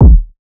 kick maestro.wav